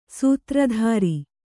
♪ sūtra dhāri